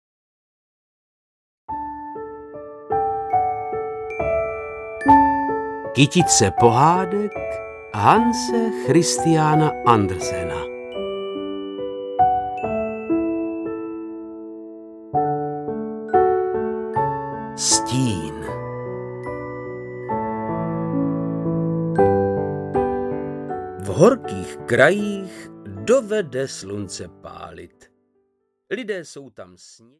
Stín audiokniha
Ukázka z knihy
• InterpretVáclav Knop